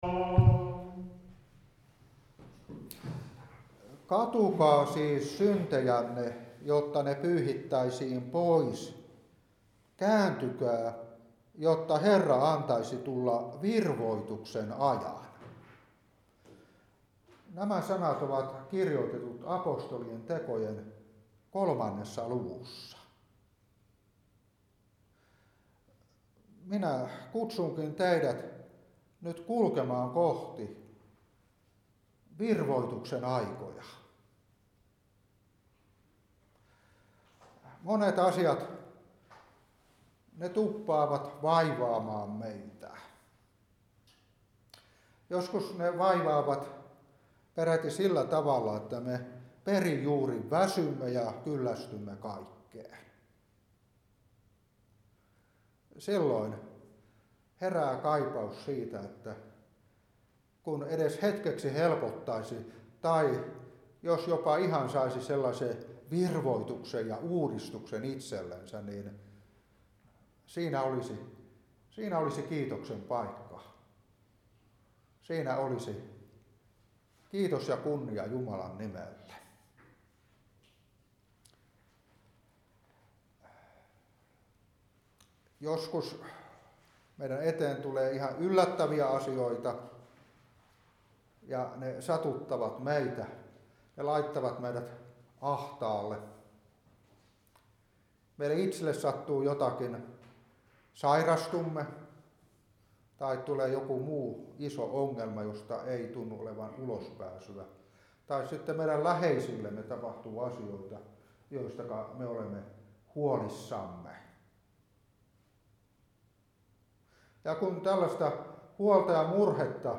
Opetuspuhe 2022-2. Apt.3:19,20. Hepr.9:27. Mark.1:15. Ef.1:7.